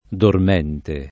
dormente
dormente [ dorm $ nte ]